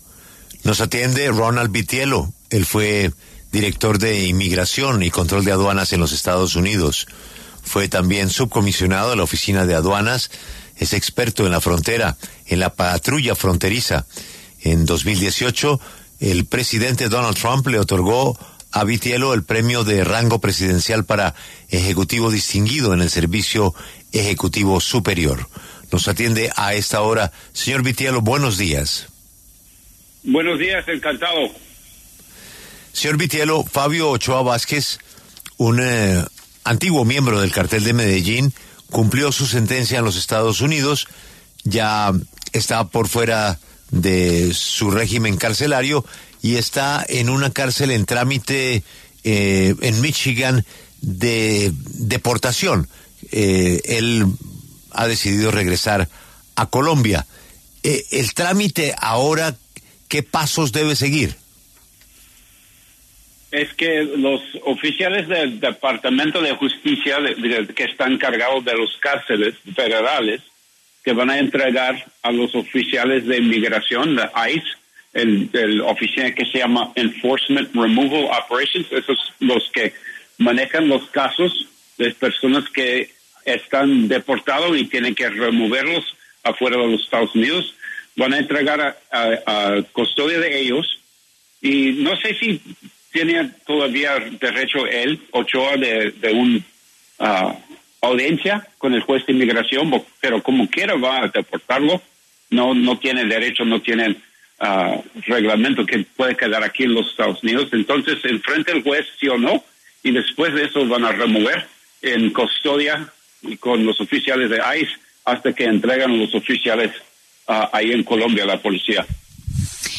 Ronald Vitiello, exdirector interino del Servicio de Inmigración y Control de Aduanas de los EE.UU. explicó en La W el paso a seguir en la deportación del exnarco de Medellín, Fabio Ochoa.